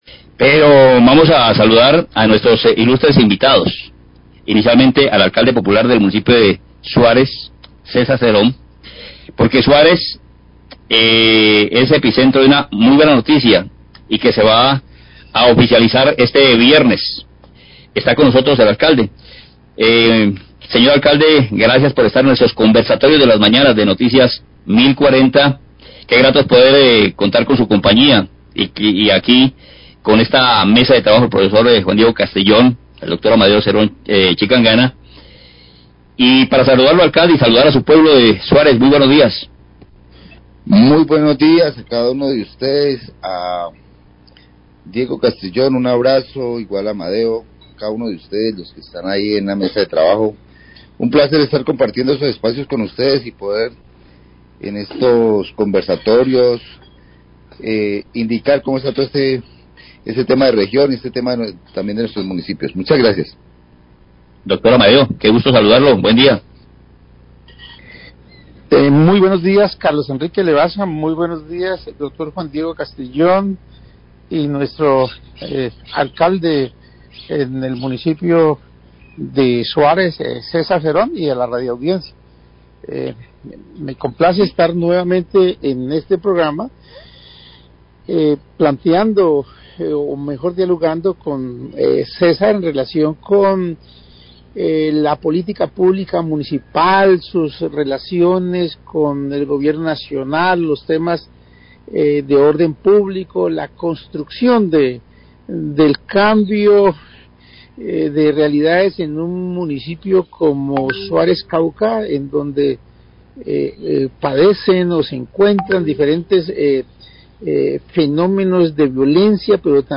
Radio
En la sección "Conversatorios de la Mañana" hablan de los problemas de orden público en algunos municipíos del Cauca. El Alcalde de Suárez, César Cerón, iniica su participación destacando la visita del presidente Petro, la vicepresidenta Márquez y el Ministro de Educación para oficializar este viernes la entrega de una sede para la Univalle en este municiío nortecaucano.